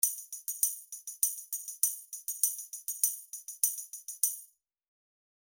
FishMarket_tambourine.mp3